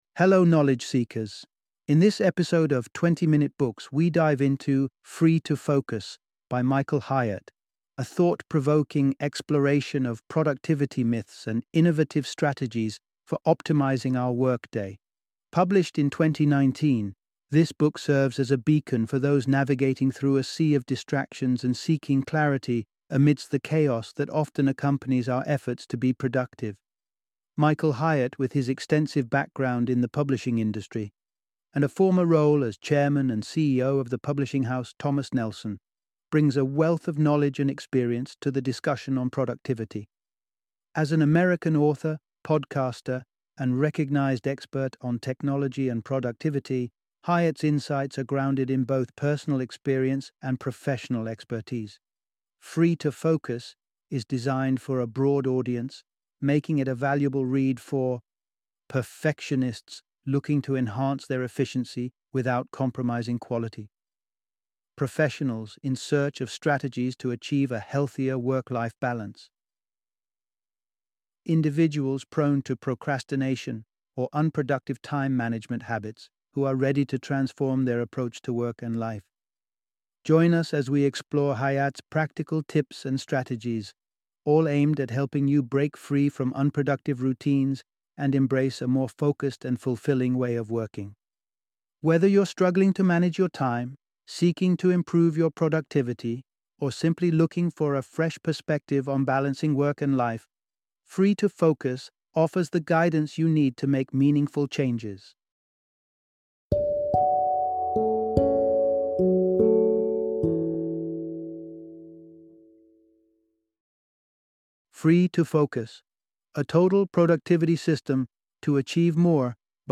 Free to Focus - Audiobook Summary